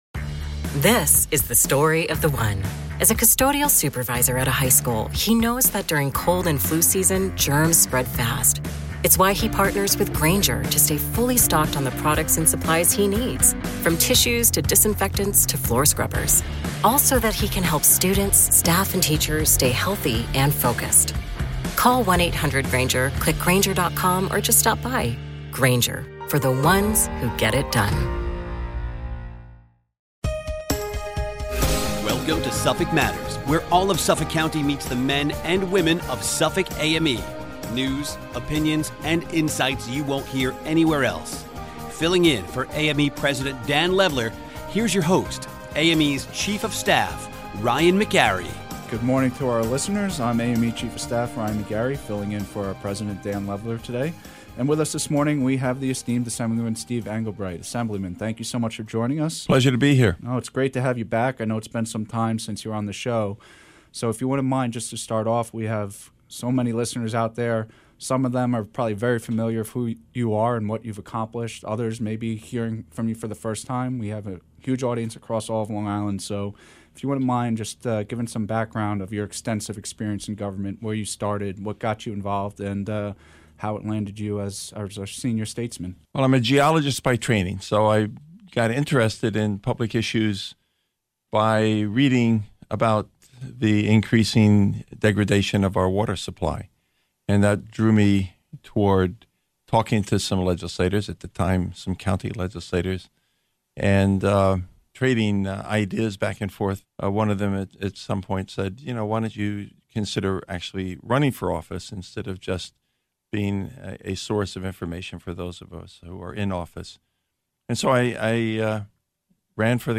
speaks with New York State Assemblyman Steve Englebright